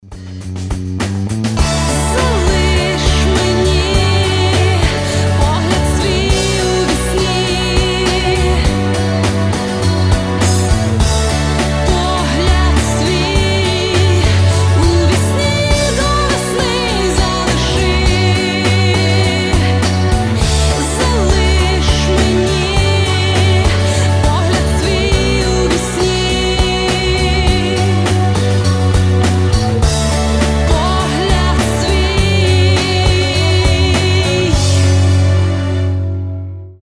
• Качество: 320, Stereo
гитара
громкие
женский вокал
90-е